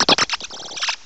cry_not_noibat.aif